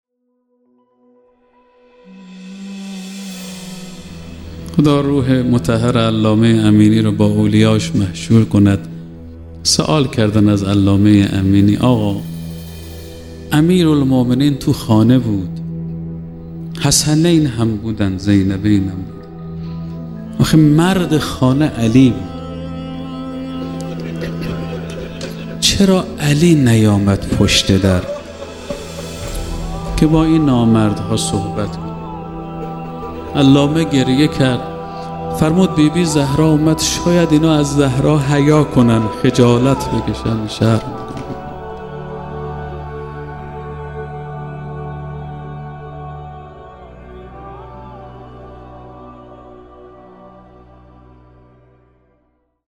ایکنا به مناسبت ایام سوگواری شهادت دخت گرامی آخرین پیام‌آور نور و رحمت، مجموعه‌ای از سخنرانی اساتید اخلاق کشور درباره شهادت ام ابی‌ها (س) با عنوان «ذکر خیر ماه» منتشر می‌کند.